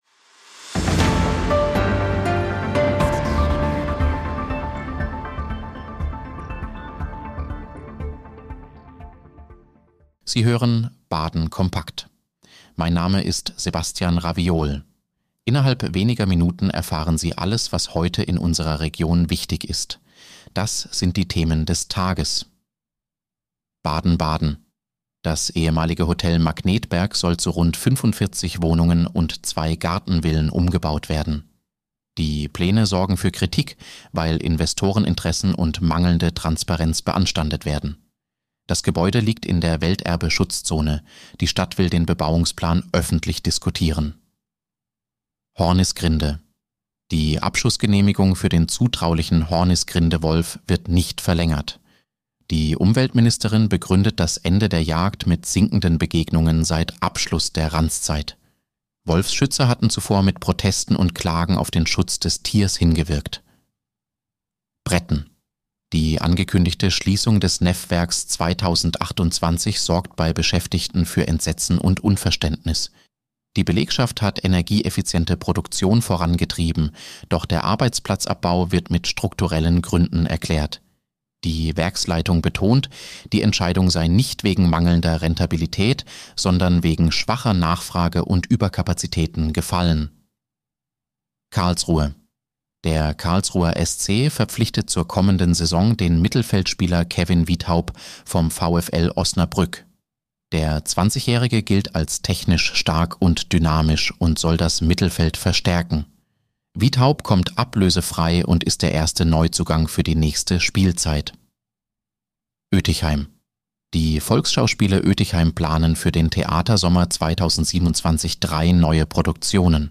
Nachrichtenüberblick: Abschussgenehmigung für Hornisgrinde-Wolf gestoppt